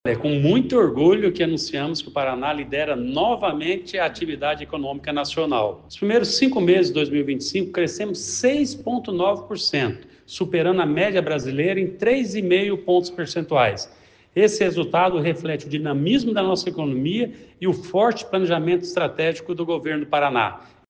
Sonora do secretário do Planejamento, Ulisses Maia, sobre o Paraná ter o maior crescimento econômico do Brasil nos primeiros cinco meses de 2025